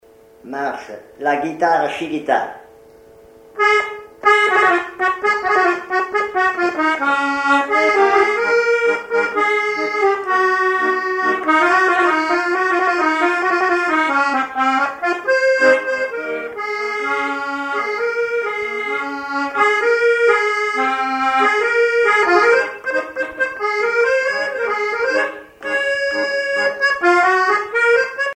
accordéon(s), accordéoniste
danse : tango
Pièce musicale inédite